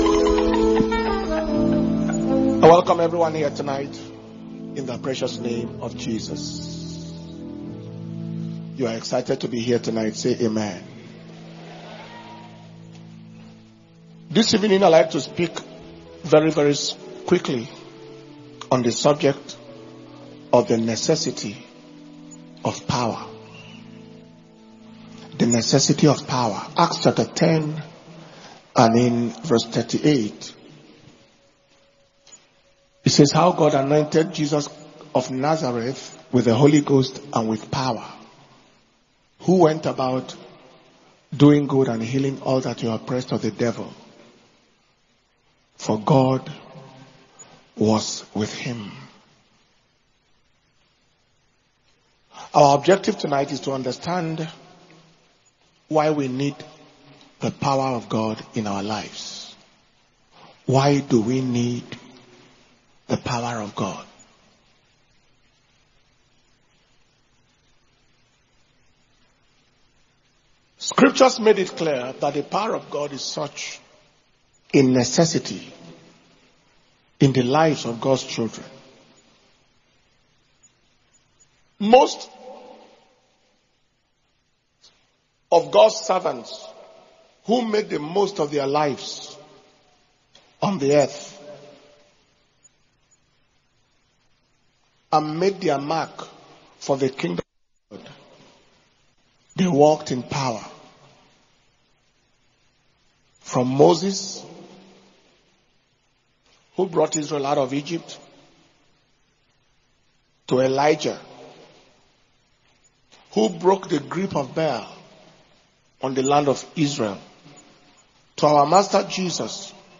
Dr Paul Enenche - The Necessity of Power mp3 - Power Communion Service Wednesday 13th November, 2024 Message